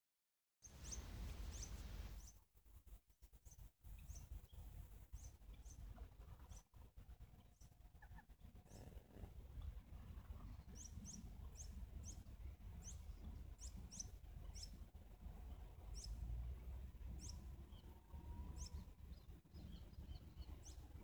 Sharp-billed Canastero (Asthenes pyrrholeuca)
Life Stage: Adult
Detailed location: Colonia Ayuí, Paso del Águila
Condition: Wild
Certainty: Photographed, Recorded vocal